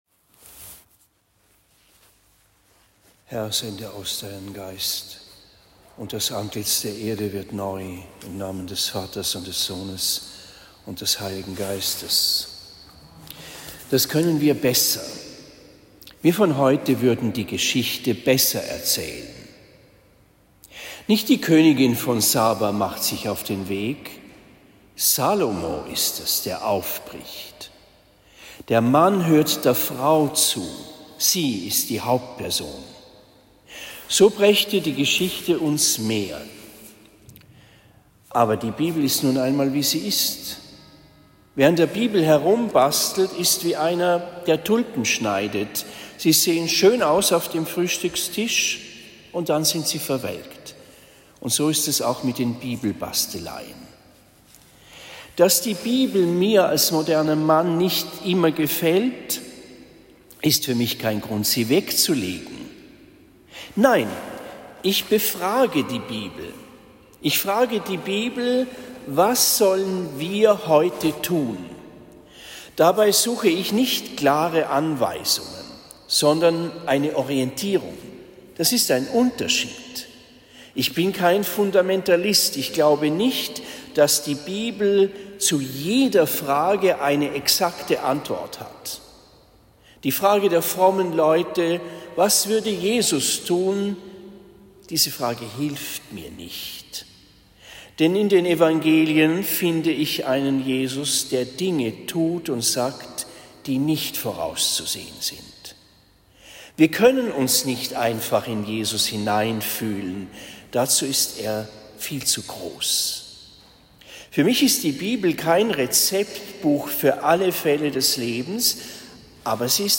Predigt in Marktheidenfeld St.-Laurentius am 07. Februar 2024